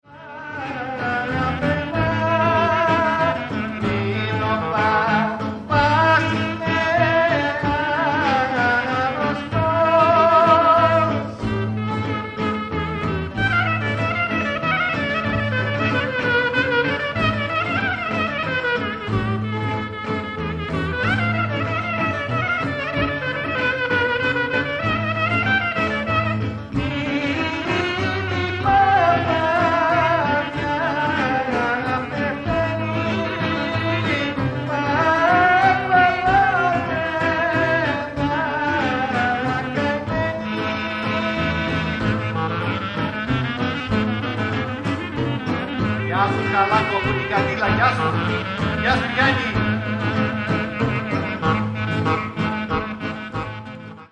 Tsamikos